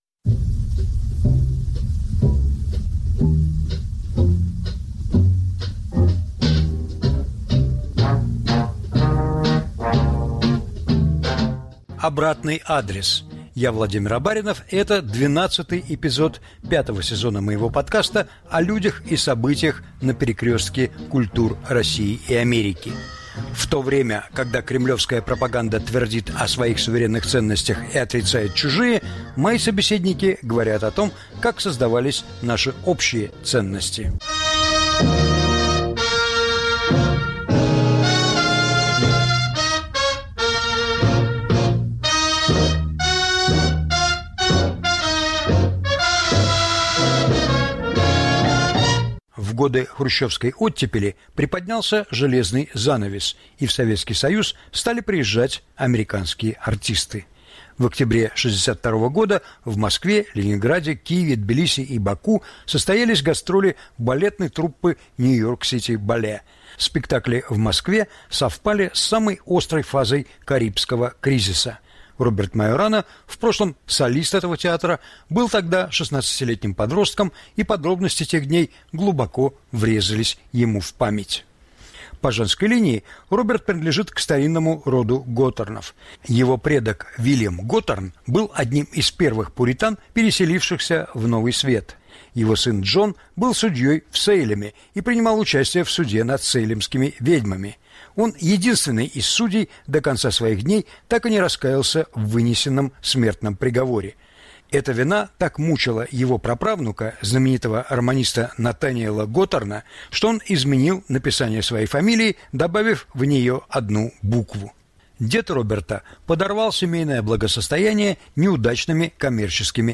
Интервью с танцовщиком